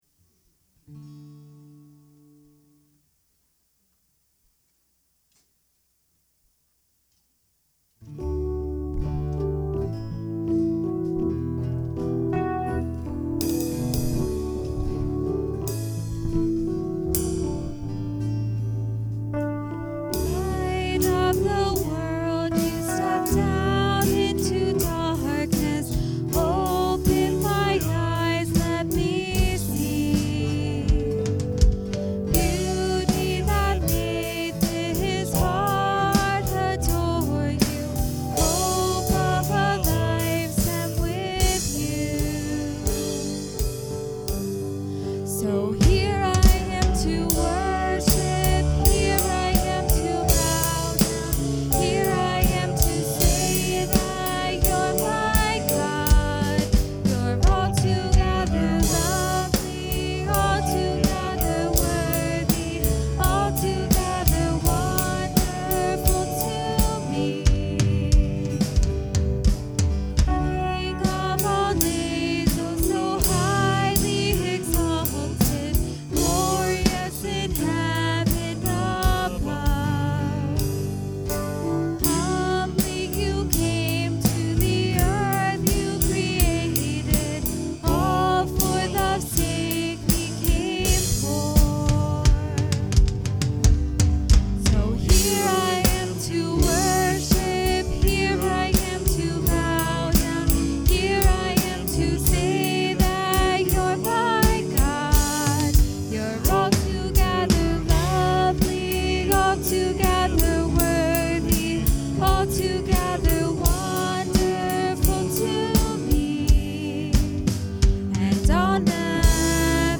John 14:1-14 Service Type: Sunday Morning Worship Intro